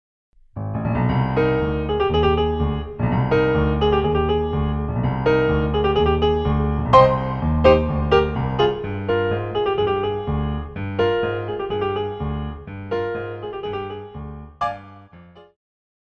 Classical,Pop
Flute
Piano
Instrumental
World Music
Only backing